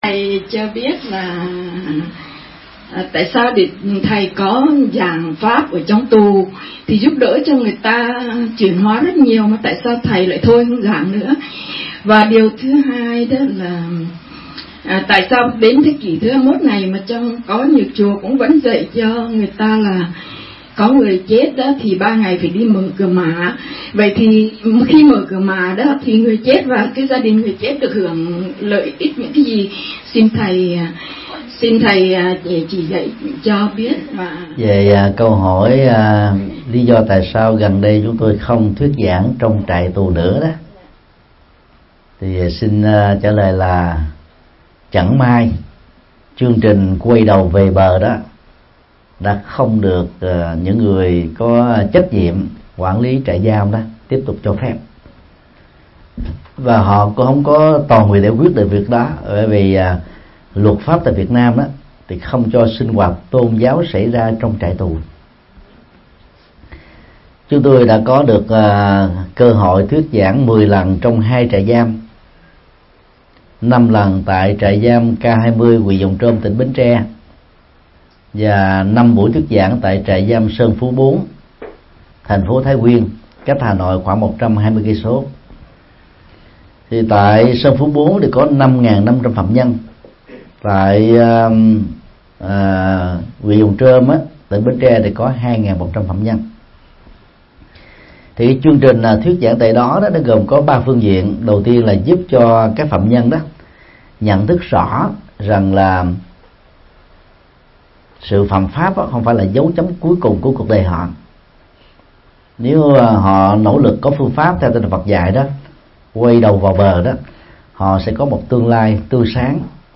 Mp3 Vấn đáp: Hoằng pháp trong trại giam, mở cửa mã – Thầy Thích Nhật Từ Giảng tại thiền thất Thường Lạc, Paris, Pháp, ngày 27 tháng 6 năm 2015